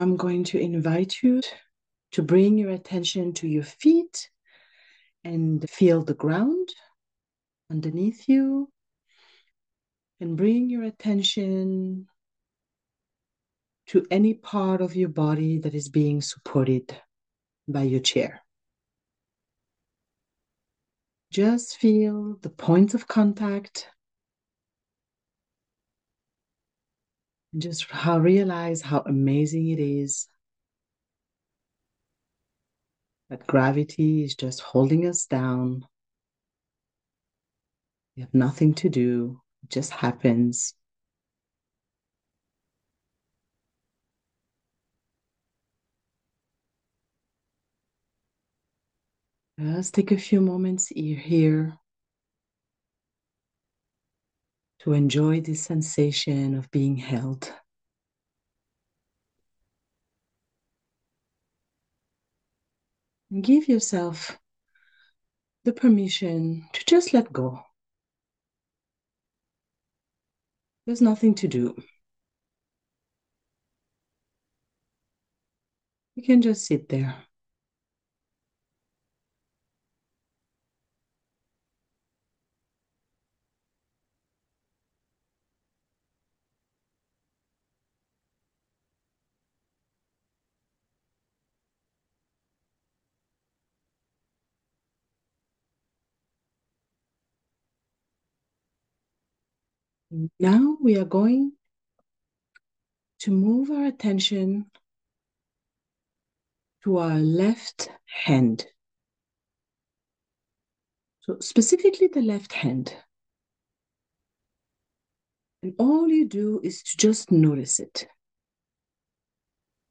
Grounding Meditation
A simple guided visualization to help you disconnect from fight or flight and build sustainable inner strength through a 10-minute practice